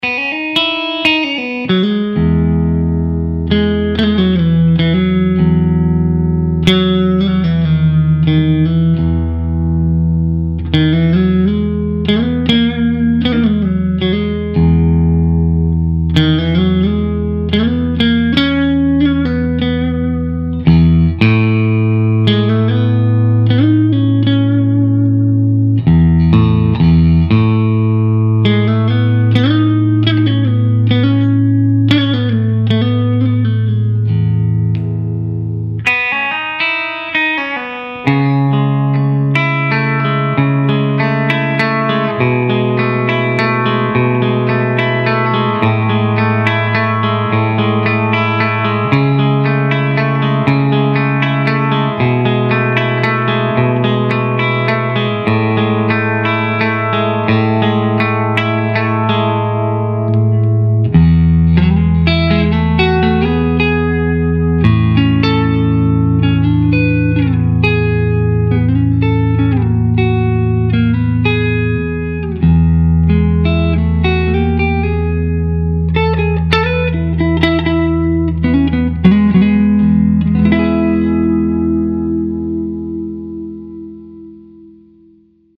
O Falante Crazy Diamond da BGT SPEAKER possui médios controlados e suaves, graves bem presentes e firmes, médios agudos cristalino e não ardido.
CRUNCH